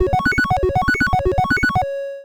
retro_beeps_success_01.wav